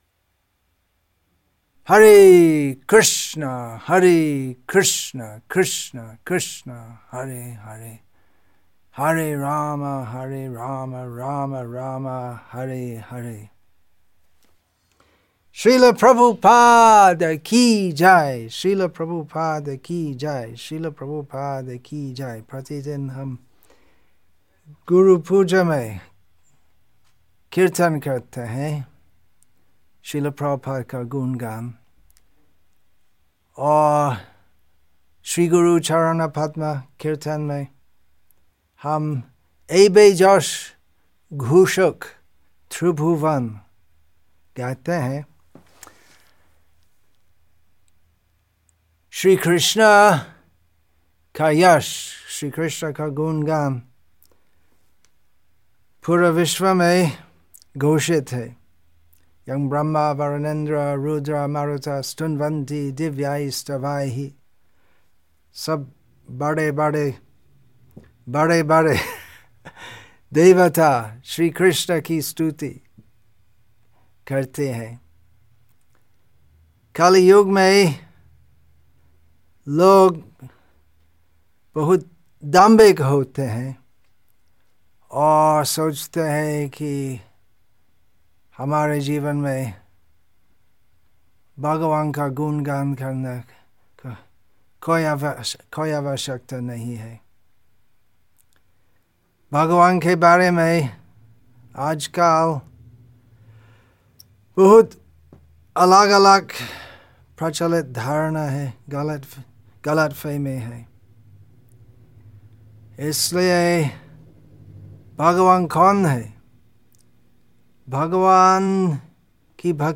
श्रील प्रभुपाद की व्यास पूजा पर प्रवचन